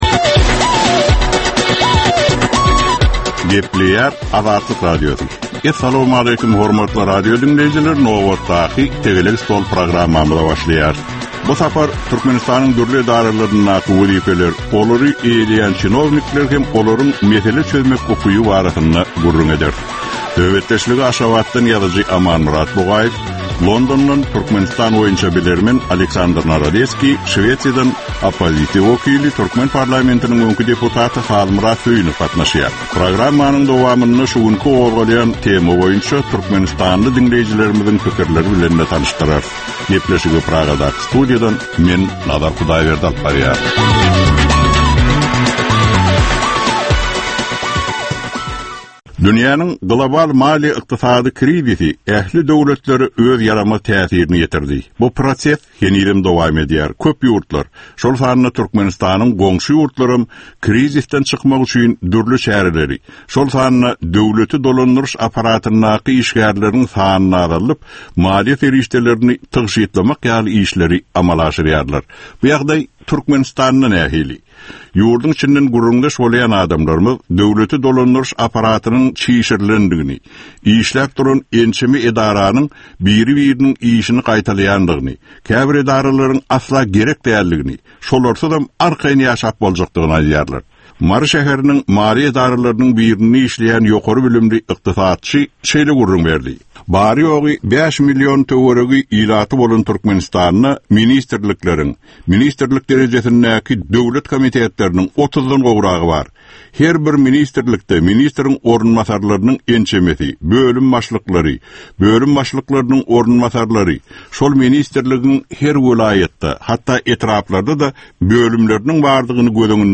Jemgyýetçilik durmuşynda bolan ýa-da bolup duran soňky möhum wakalara ýa-da problemalara bagyşlanylyp taýýarlanylýan ýörite Tegelek stol diskussiýasy. 25 minutlyk bu gepleşhikde syýasatçylar, analitikler we synçylar anyk meseleler boýunça öz garaýyşlaryny we tekliplerini orta atýarlar.